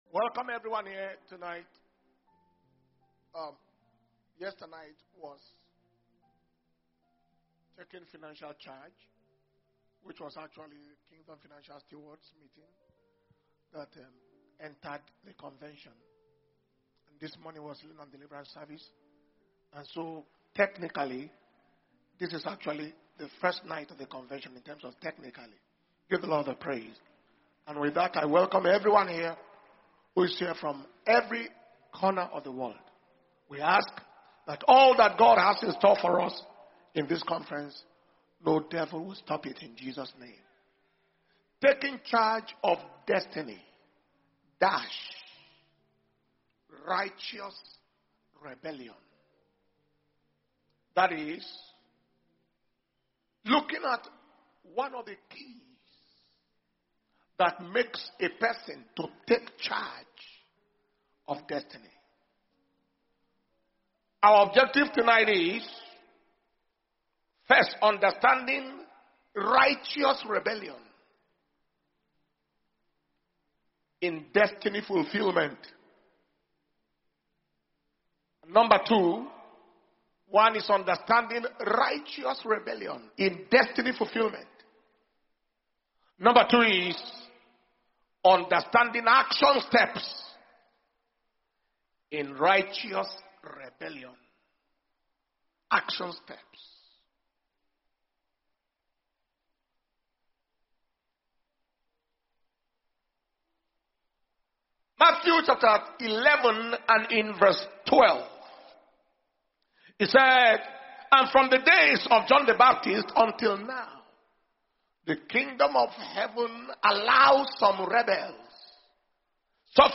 May 2025 Destiny Recovery Convention - Day 1 Evening Session